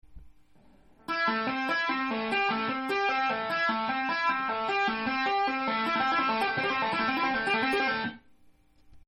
ライトハンド奏法フレーズ５
右手のタッピングポジションが変わりますので少し、繊細なフレーズですが